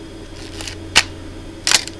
【シャッター音が聞けます】(wav FILE・・・44KB)